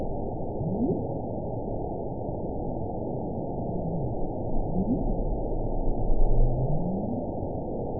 event 922021 date 12/25/24 time 10:21:26 GMT (4 months, 1 week ago) score 9.28 location TSS-AB10 detected by nrw target species NRW annotations +NRW Spectrogram: Frequency (kHz) vs. Time (s) audio not available .wav